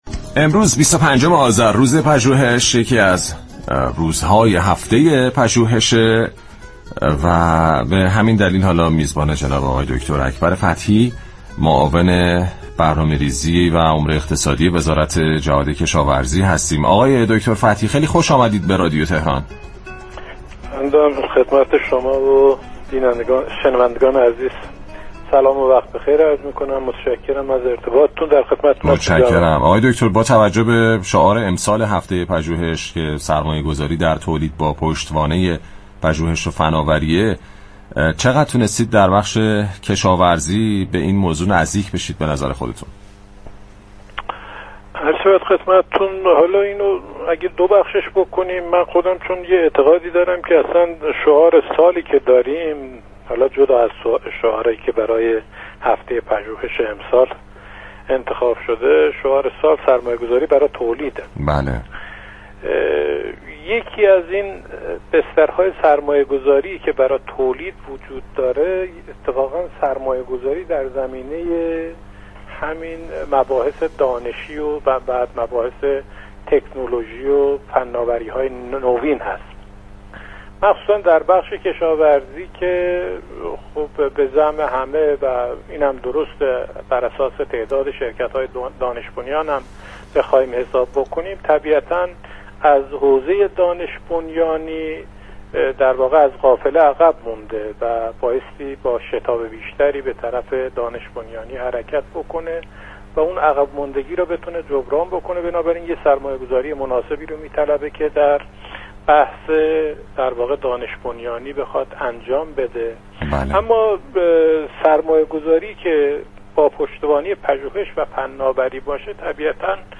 همزمان با 25 آذر و روز پژوهش، معاون برنامه‌ریزی و امور اقتصادی وزارت جهاد كشاورزی در گفتگو با رادیو تهران اعلام كرد كه اگرچه دانش و پژوهش‌های لازم در بخش كشاورزی تولید شده، اما چالش اصلی این بخش، به‌كارگیری و نفوذ دانش در واحدهای تولیدی است؛ موضوعی كه با سرمایه‌گذاری هدفمند، آموزش و ترویج می‌تواند به افزایش بهره‌وری و پایداری تولید منجر شود.